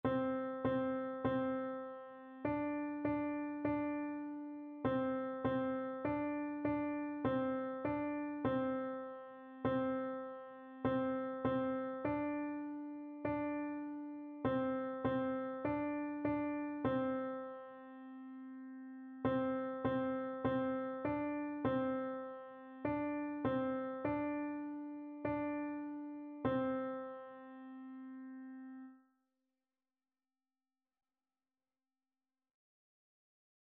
4/4 (View more 4/4 Music)
Piano  (View more Beginners Piano Music)
Classical (View more Classical Piano Music)